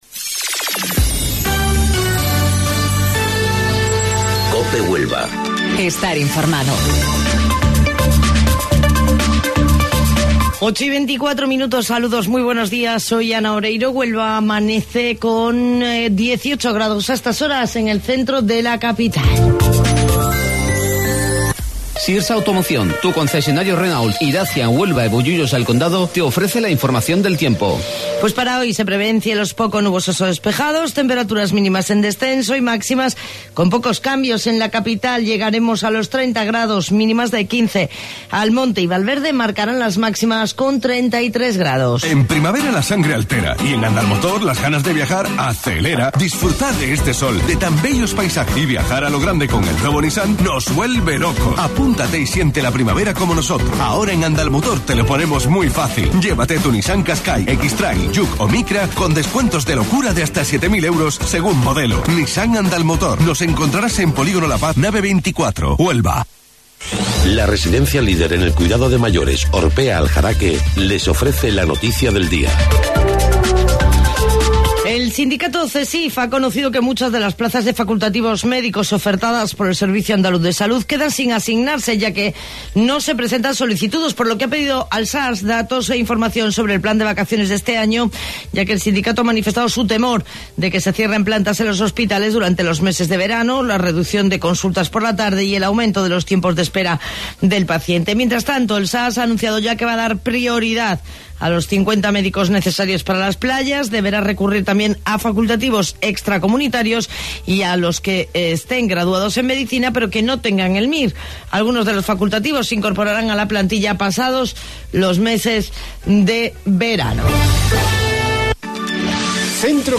AUDIO: Informativo Local 08:25 del 30 de Mayo